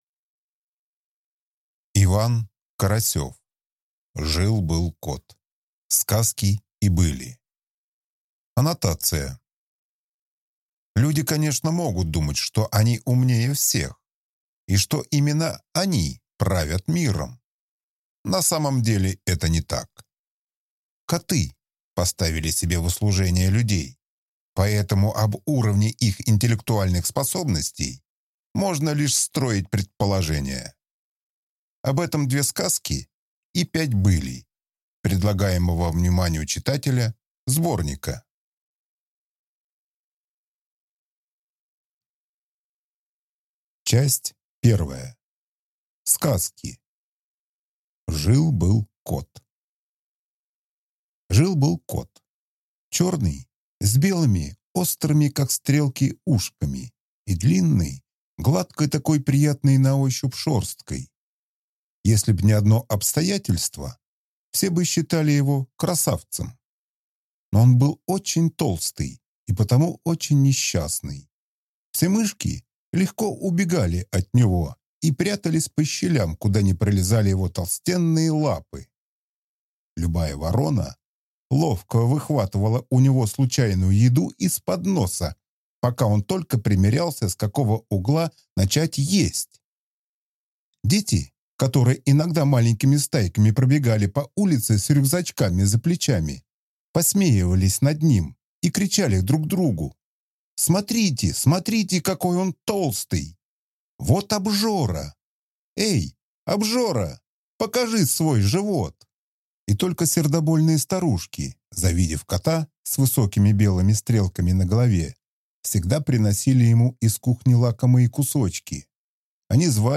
Аудиокнига Жил-был кот. Сказки и были | Библиотека аудиокниг